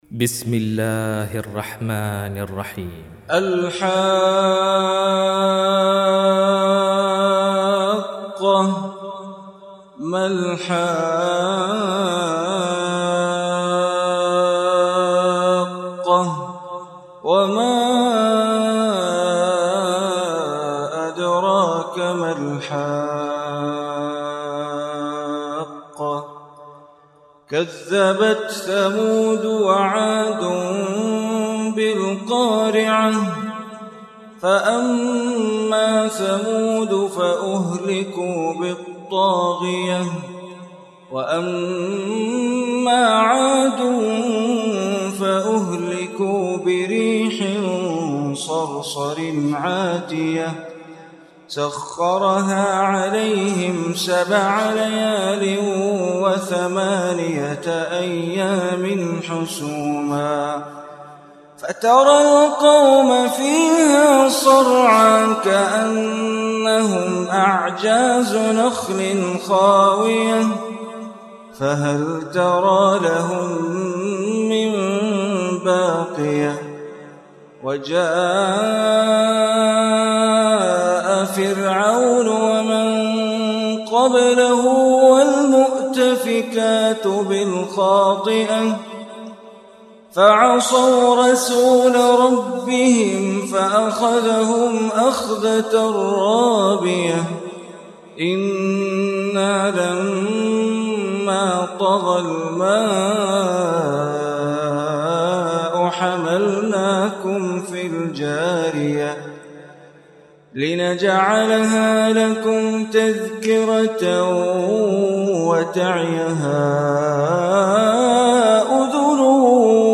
Surah Haqqah Recitation by Sheikh Bandar Baleela
Surat Al-Haqqah, listen online mp3 tilawat / recitation in the beautiful voice of Imam e Kaaba Sheikh Bandar Baleela.